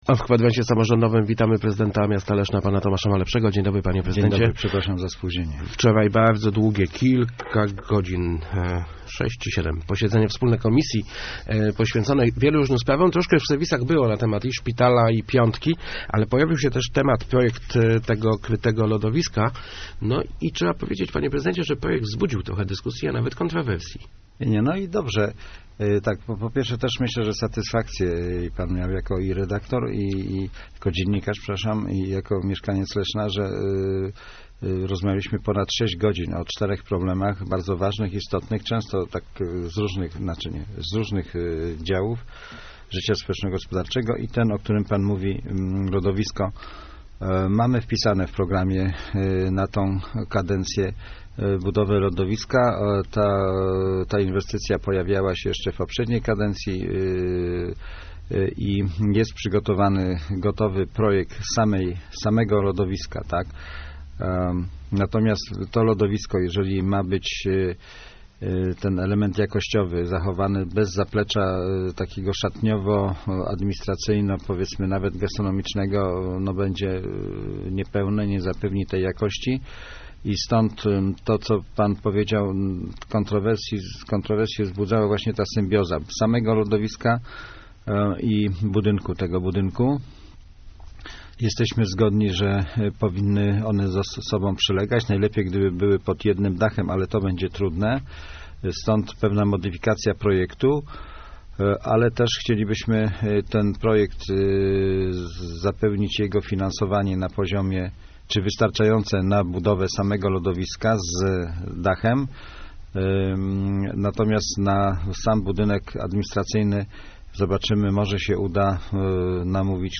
Gościem Kwadransa był prezydent Tomasz Malepszy ...